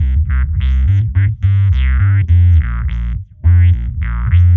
Index of /musicradar/dub-designer-samples/105bpm/Bass
DD_JBassFX_105C.wav